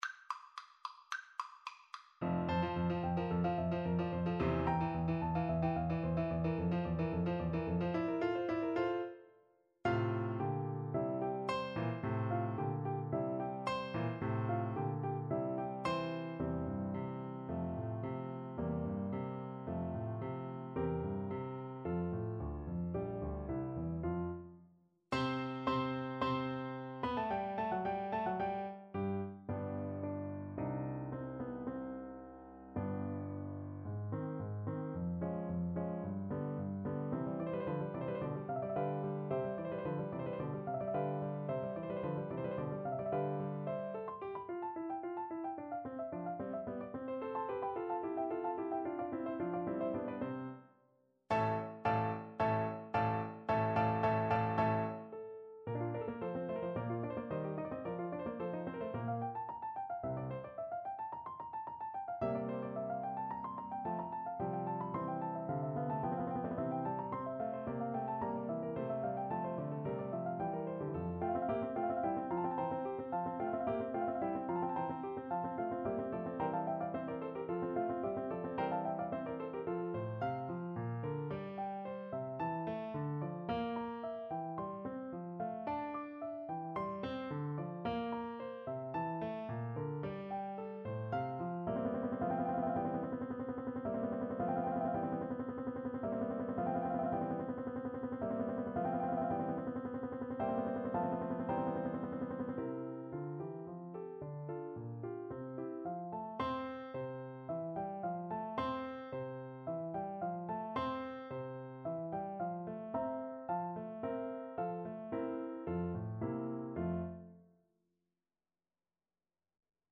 Play (or use space bar on your keyboard) Pause Music Playalong - Piano Accompaniment Playalong Band Accompaniment not yet available reset tempo print settings full screen
Allegro assai =220 (View more music marked Allegro)
F major (Sounding Pitch) G major (Tenor Saxophone in Bb) (View more F major Music for Tenor Saxophone )
Classical (View more Classical Tenor Saxophone Music)